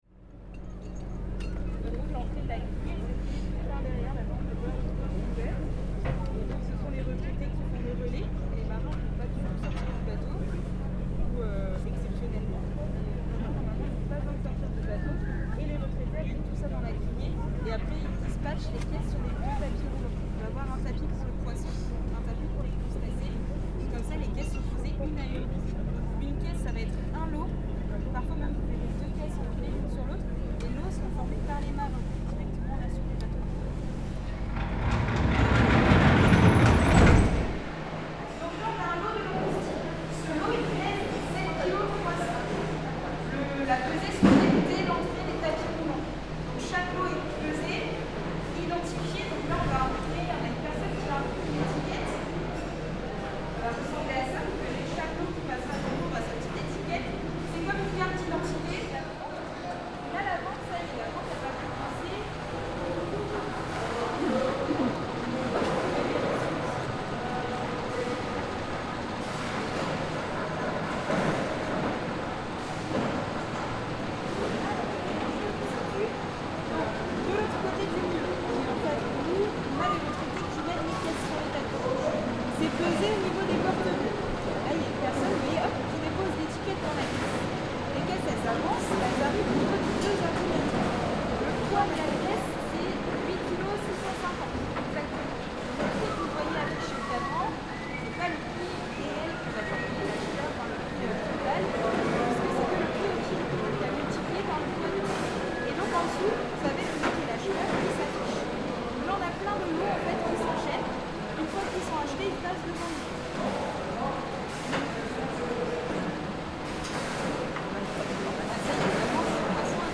Guided tour of the fish auction at Guilvinec harbour.
Throughout the tour, our guide speaks to us through a microphone, relaying information to us via earpieces because the auction hall is so noisy. We learn about the journey taken by the fish and shellfish from the boat to the lorries, hear a description of how the auction works, and soak up the atmosphere of the place.